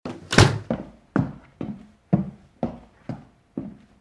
ShagiVerh.ogg